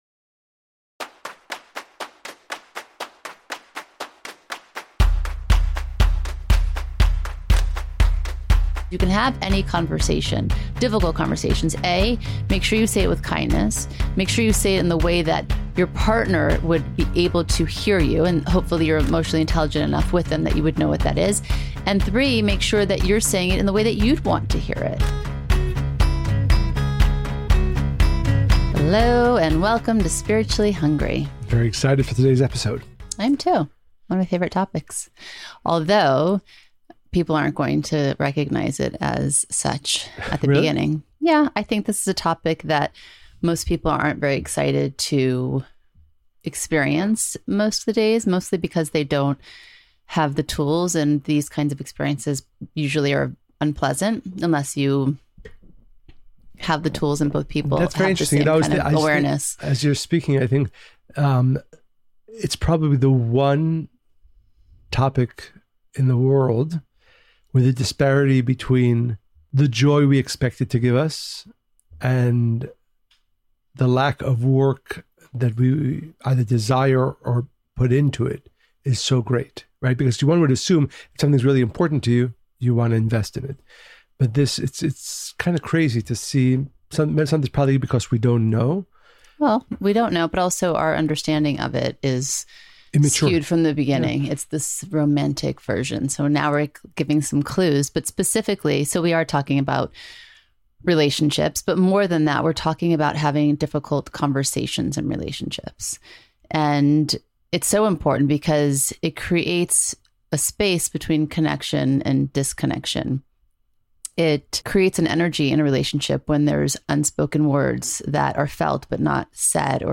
Join them for a down-to-earth conversation about the big life questions that spark your curiosity the most.